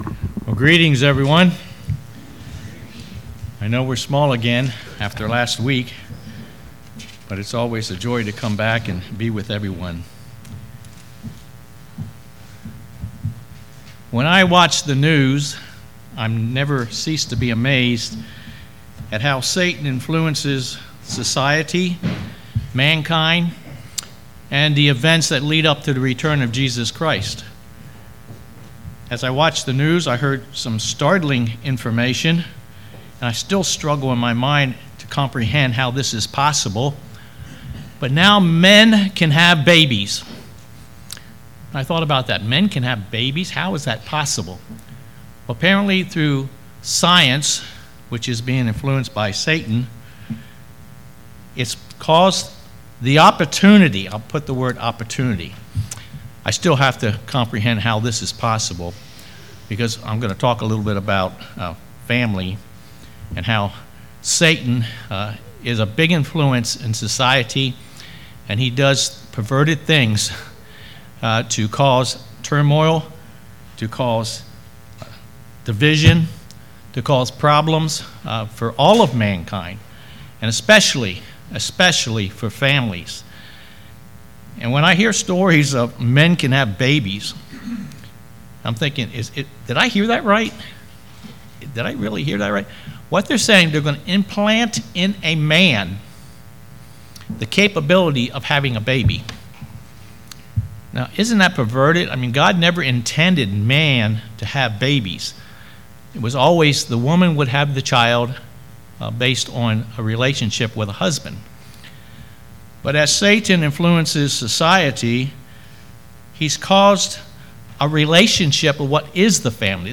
Sermons
Given in Lewistown, PA